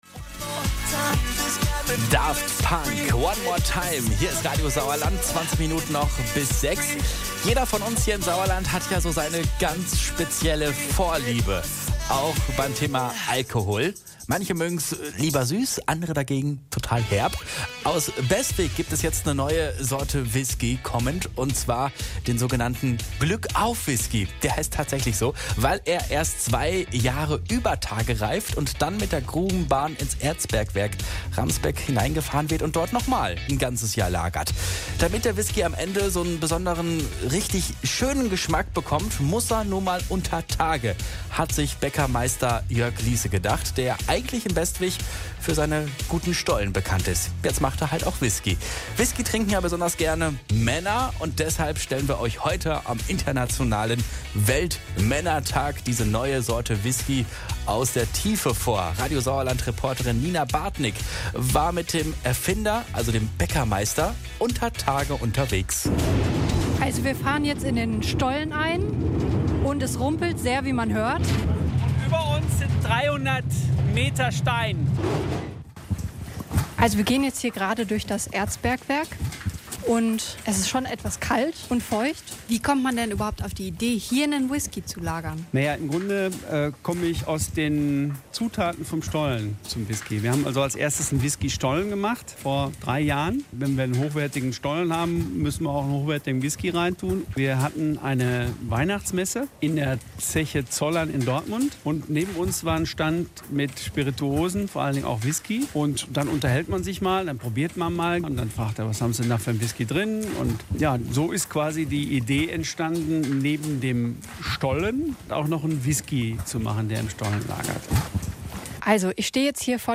Radio Sauerland Beiträge vom 19.11.2019 zum Glück auf Whisky
mitschnitt-whisky-2.mp3